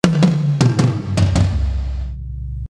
Um mehr Druck zu entfalten, platziere ich den Eventide Omnipressor hinter dem Hall:
Der Omnipressor pumpt bei diesen Einstellungen in den Pausen extrem und zieht das Release der Toms und den Rauschpegel hoch.